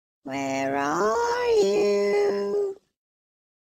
Звук бабушки из игры Granny Where are you